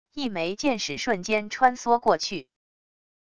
一枚箭矢瞬间穿梭过去wav音频